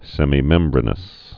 (sĕmē-mĕmbrə-nəs, sĕmī-)